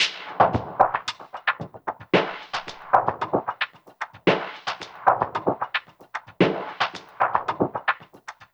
DWS SWEEP1-L.wav